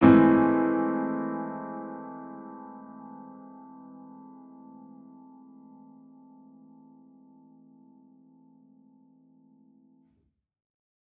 Index of /musicradar/gangster-sting-samples/Chord Hits/Piano
GS_PiChrd-Esus4min6.wav